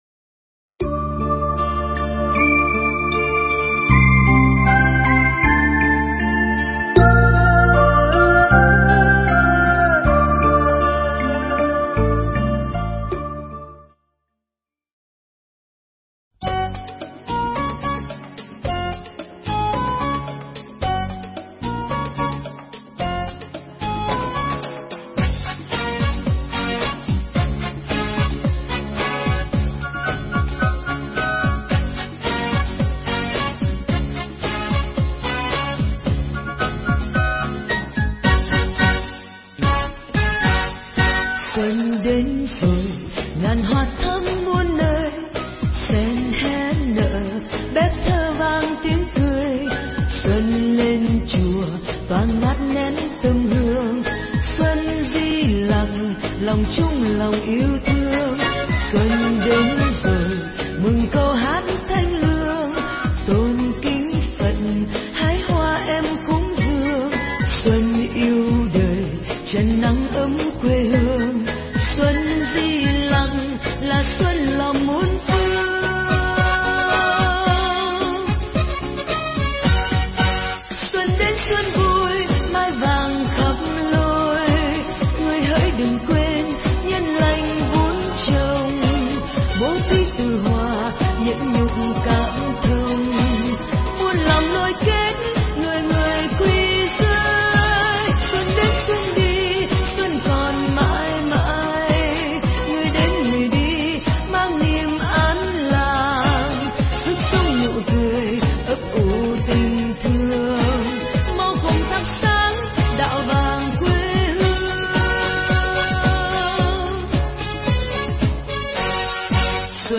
Nghe Mp3 thuyết pháp Khó Vẫn Làm Được
Mp3 pháp thoại Khó Vẫn Làm Được